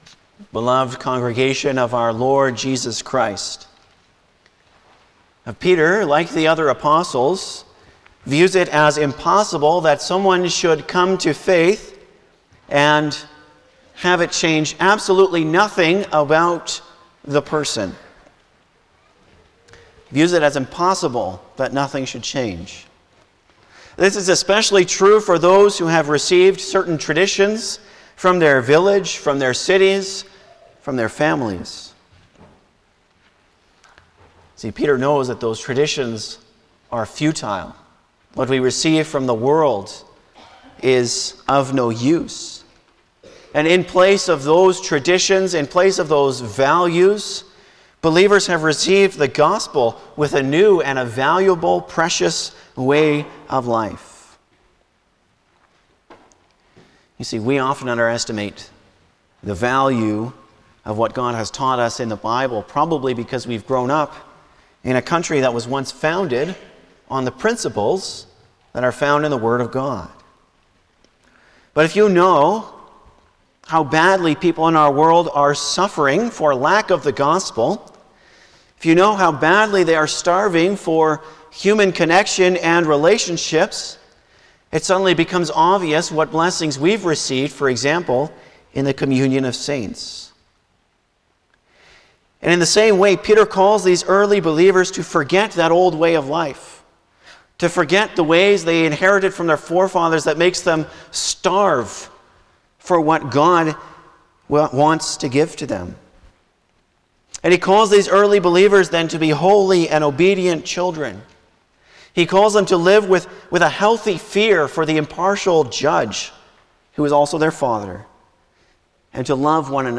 Service Type: Sunday morning
09-Sermon.mp3